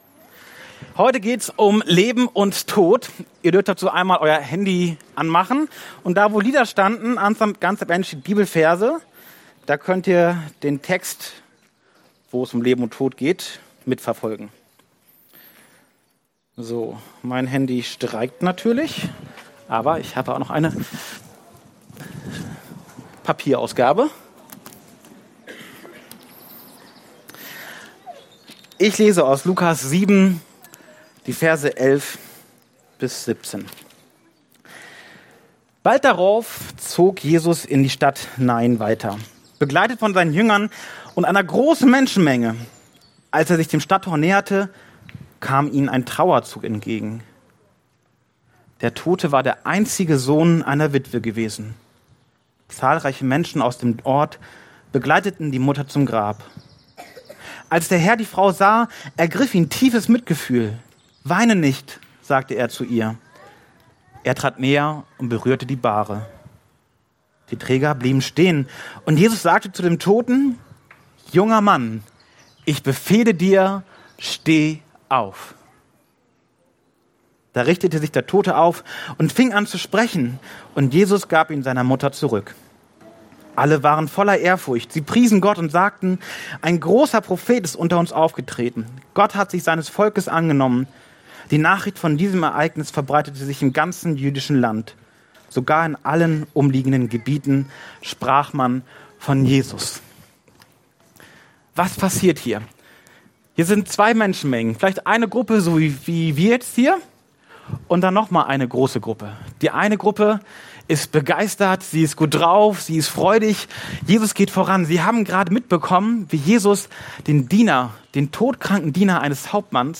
Taufgottesdienst – Es geht um Leben und Tod
Passage: Lukas 7, 11-17; Johannes 11,25 Dienstart: Predigt